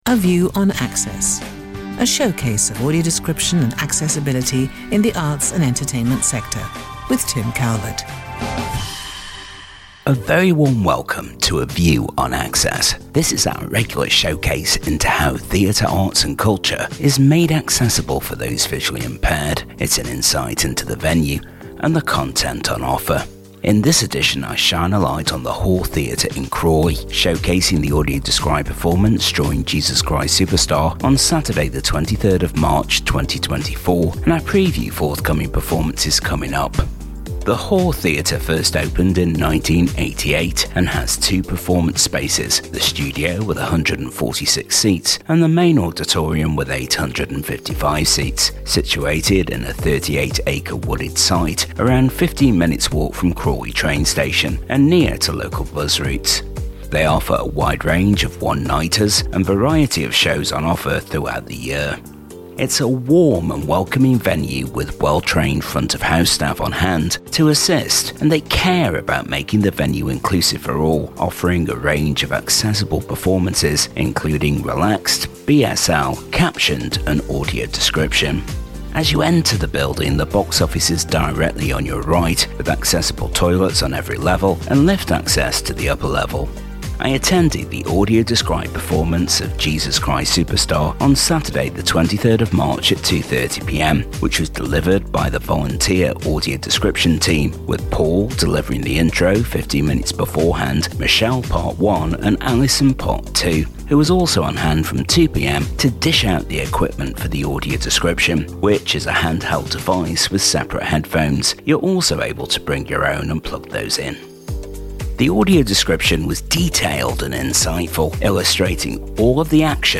The Hawth In Crawley during the audio described performance of Jesus Christ Superstar and forthcoming shows